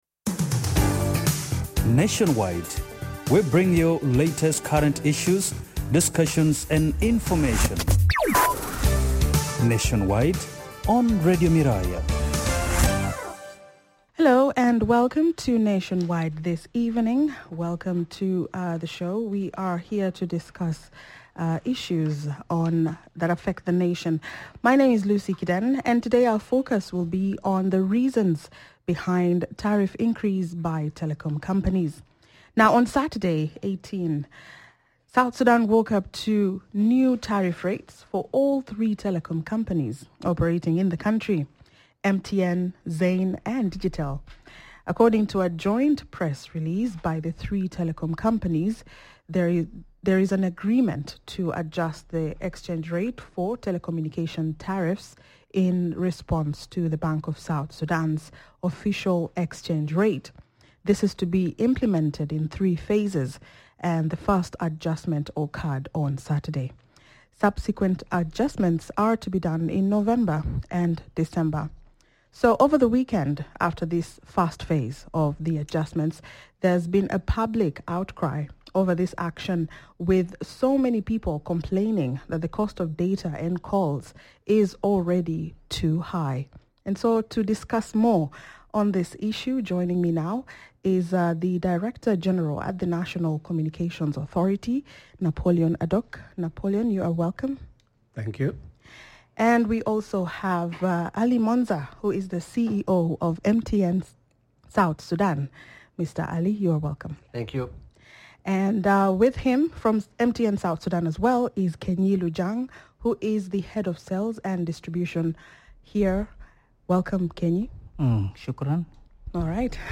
1. Napoleon Adok: Director General at the National Communications Authority